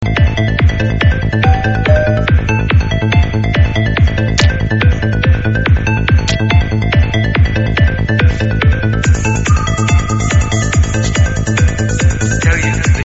Psy Tune